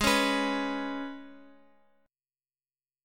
G#mbb5 chord
Gsharp-Minor Double Flat 5th-Gsharp-x,x,x,3,4,6-1-down-Guitar-Open F.m4a